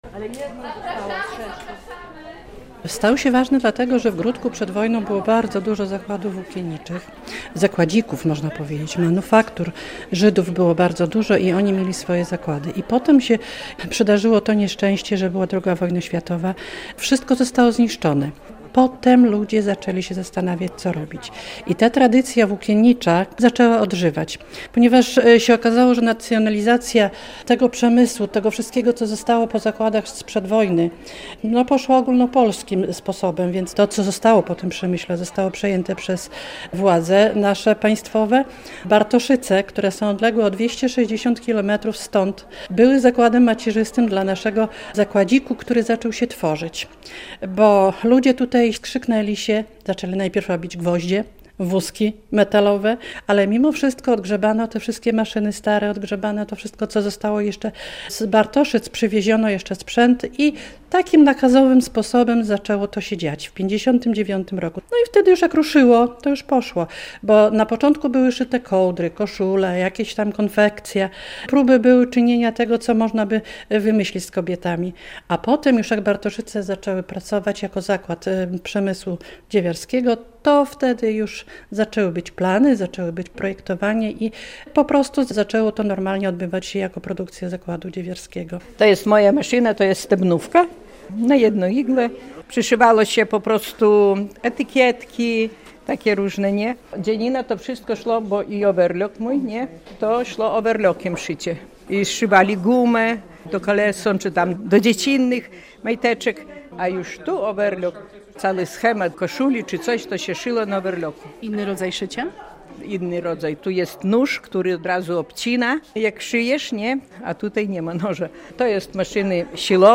Gminne Centrum Kultury w Gródku wypełniło się w sobotę wieczorem (22.10) ludźmi, którzy przyszli oglądać wystawę o zakładzie KARO.
relacja